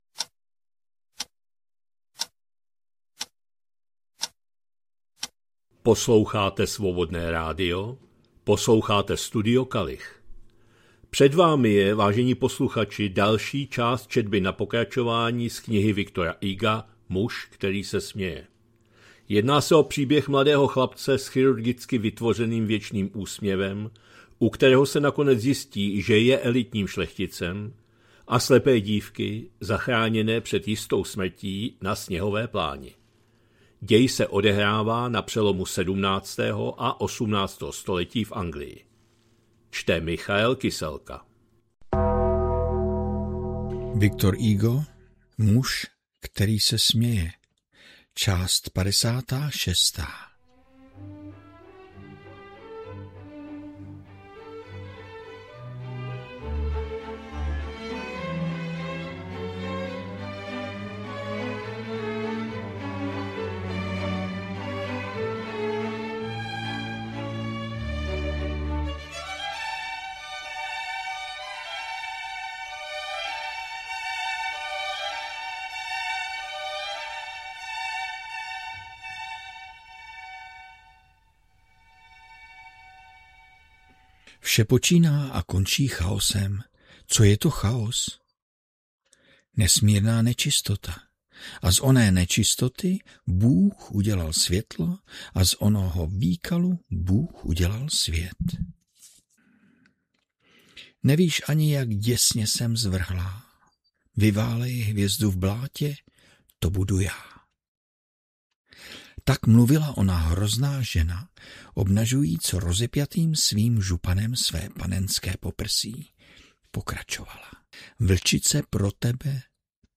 2026-04-10 - Studio Kalich - Muž který se směje, V. Hugo, část 56., četba na pokračování